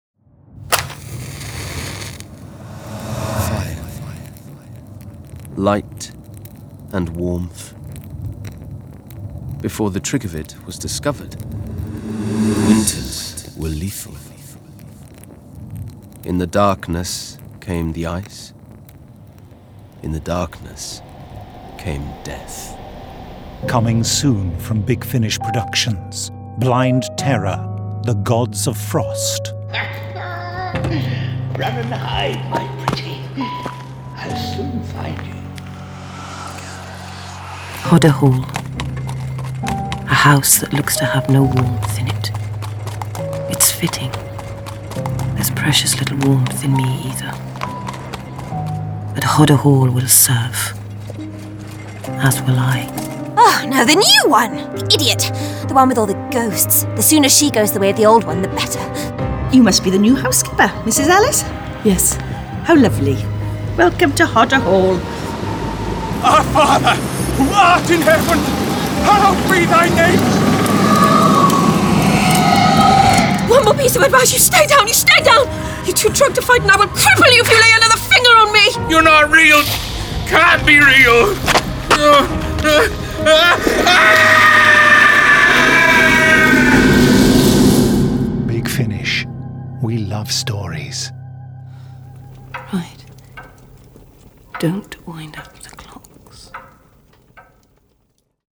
Award-winning, full-cast original audio dramas from the worlds of Doctor Who, Torchwood, Blake's 7, Class, Dark Shadows, The Avengers, Survivors, The Omega Factor, Star Cops, Sherlock Holmes, Dorian Gray, Pathfinder Legends, The Prisoner, Adam Adamant Lives, Space 1999, Timeslip, Terrahawks, Space Precinct, Thunderbirds, Stingray, Robin Hood, Dark Season, UFO, Stargate
Big Finish Originals Blind Terror: The Gods of Frost Available October 2018 Written by Guy Adams Starring Eve Myles This release contains adult material and may not be suitable for younger listeners. From US $25.13 Download US $25.13 Buy Save money with a bundle Login to wishlist 34 Listeners recommend this Share Tweet Listen to the trailer Download the trailer